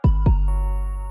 Category: Samsung Ringtones